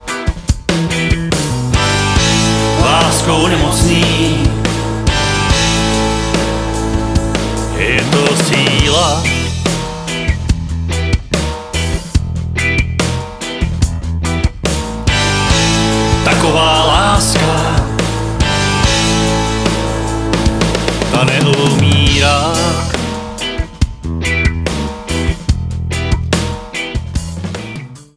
foukací harmonika
klavesy